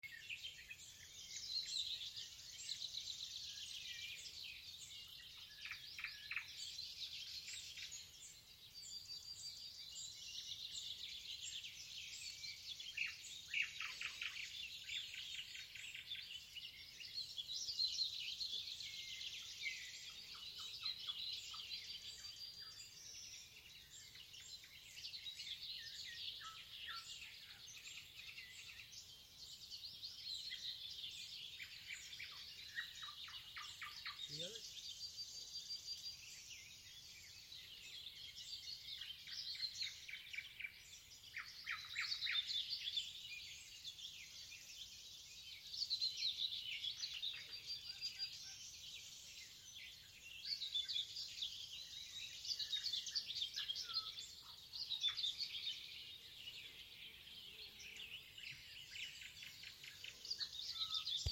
Bird Aves sp., Aves sp.
Administratīvā teritorijaRūjienas novads
StatusSinging male in breeding season